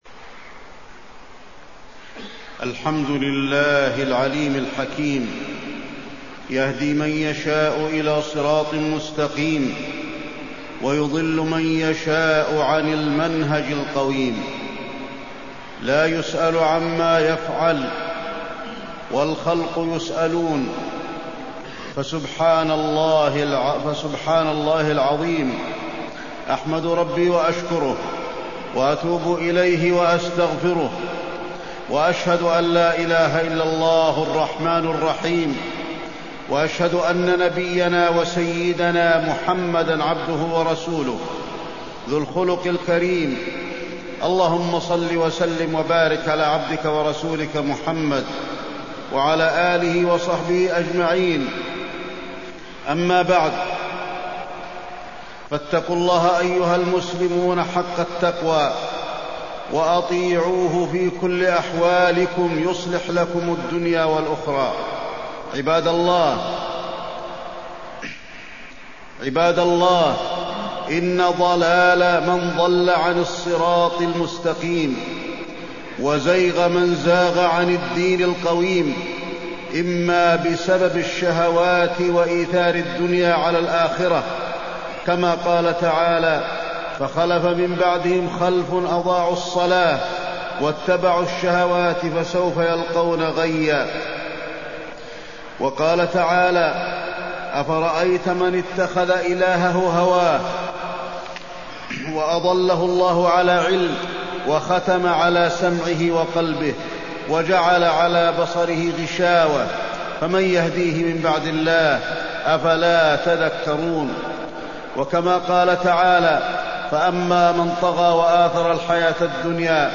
تاريخ النشر ١٦ ربيع الثاني ١٤٢٥ هـ المكان: المسجد النبوي الشيخ: فضيلة الشيخ د. علي بن عبدالرحمن الحذيفي فضيلة الشيخ د. علي بن عبدالرحمن الحذيفي فتنة الخوارج والتحذير منها